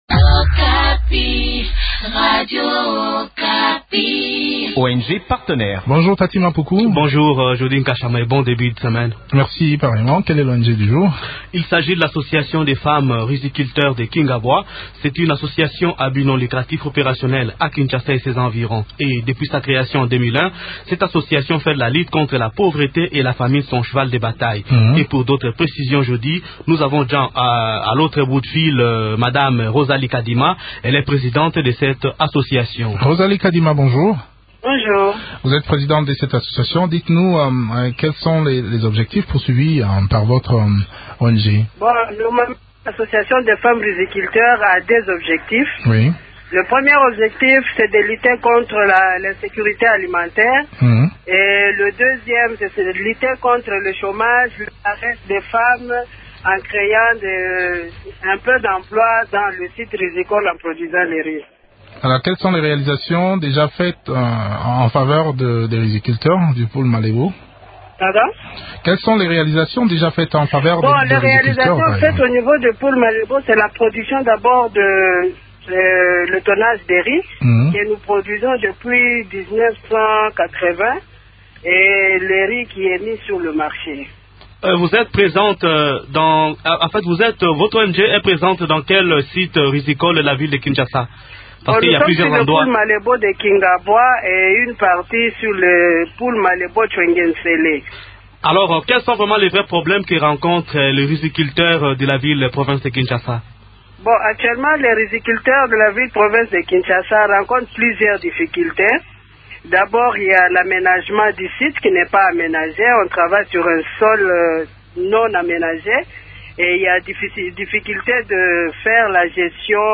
Des éléments de réponses dans cette interview